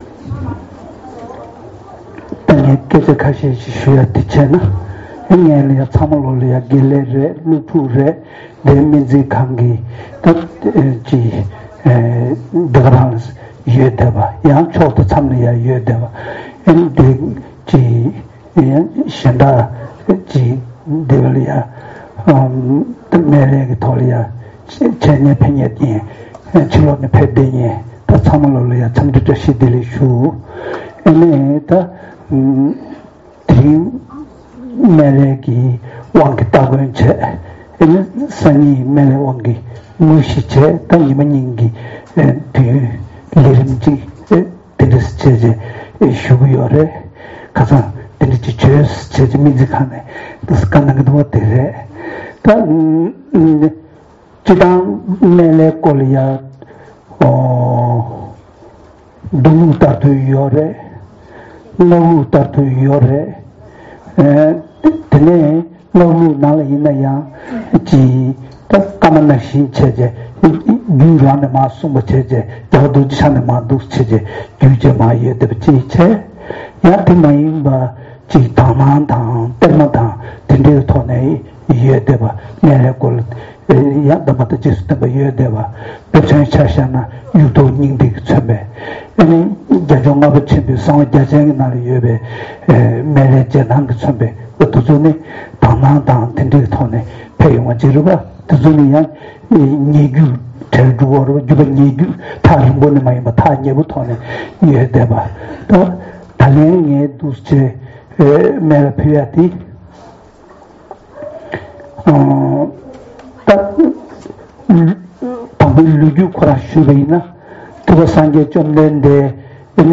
སངས་རྒྱས་སྨན་བླའི་བཀའ་དབང་གི་ངོ་སྤྲོད་སྒྲ་རྐྱང་བཀའ་སློབ་གསན།